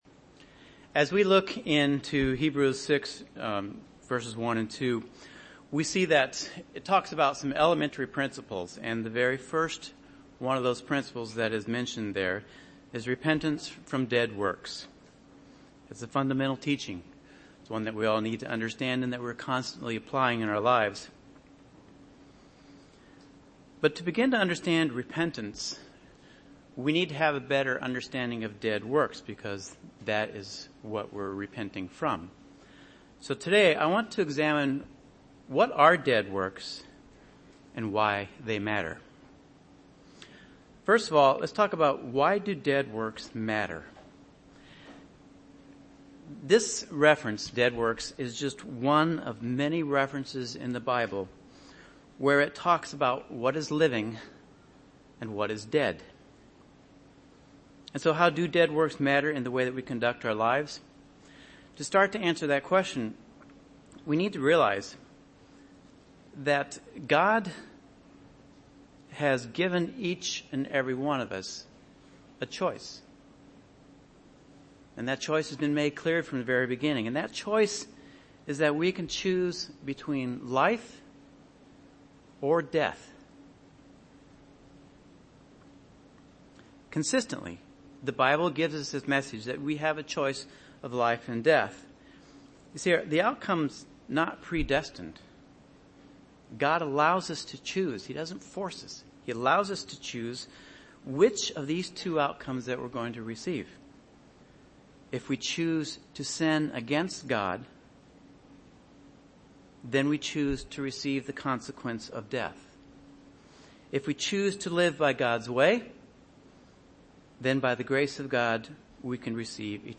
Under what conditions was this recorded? Given in Chicago, IL